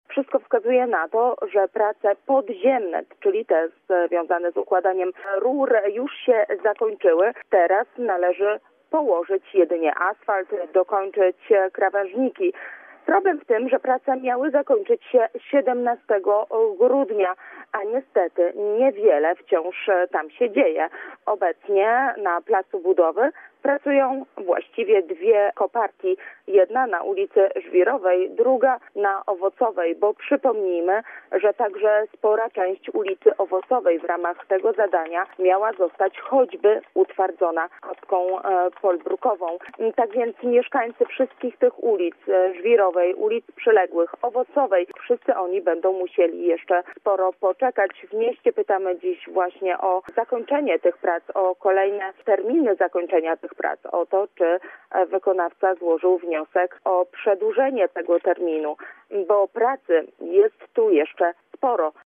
Co z pracami ul. Żwirowej i Owocowej- pyta dziś w magistracie nasza reporterka. Roboty związane z odwodnieniem w tym rejonie miały się skończyć w połowie grudnia.